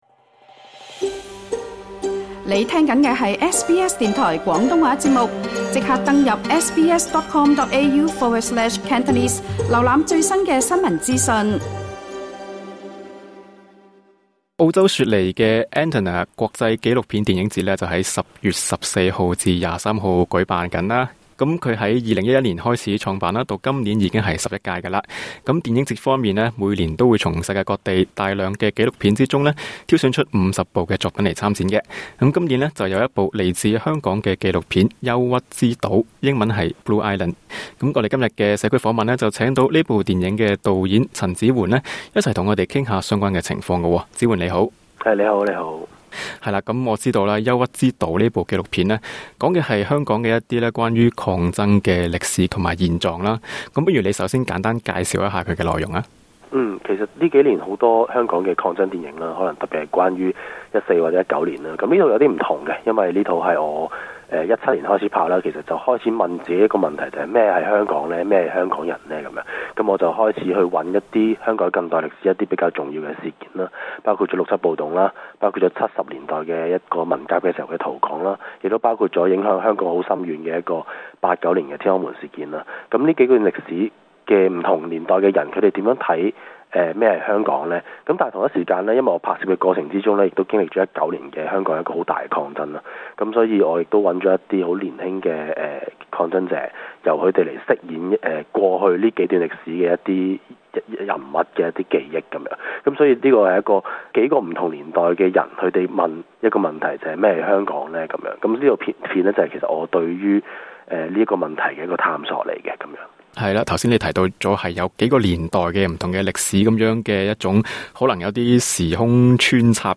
社區訪問